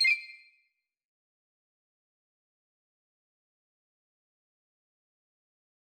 error_style_4_007.wav